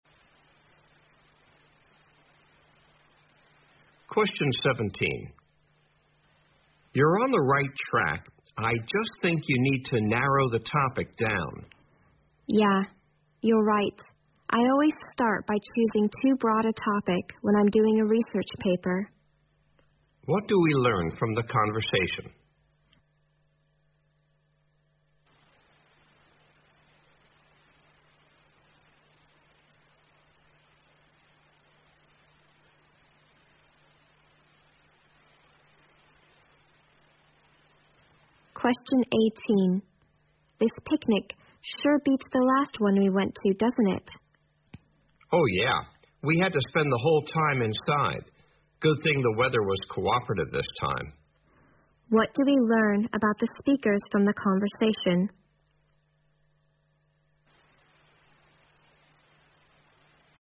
在线英语听力室023的听力文件下载,英语四级听力-短对话-在线英语听力室